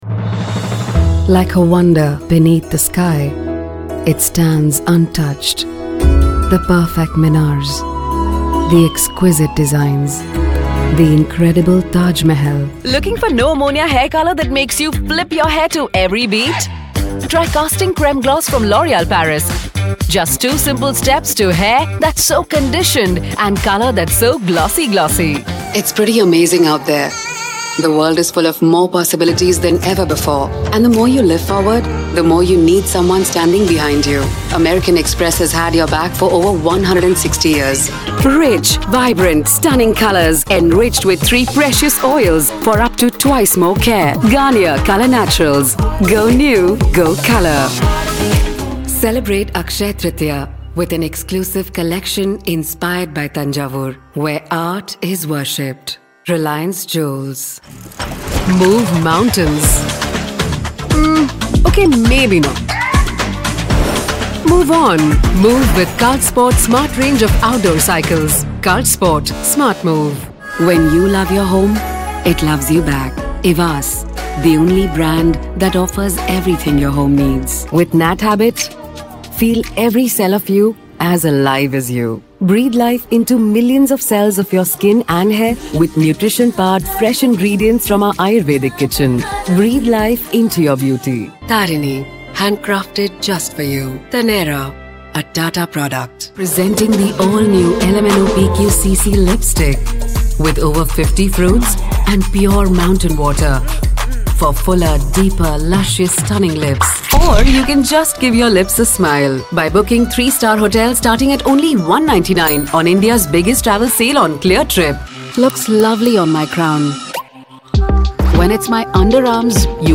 Pearson (Education) Voice Over Commercial Actor + Voice Over Jobs
Conversational, Warm, Easy-Listening, Smooth, Friendly, Relatable, Interesting, Informative, Clean, Soft-Sell, Confident, Knowledgeable The kind of voice you could listen to for hours....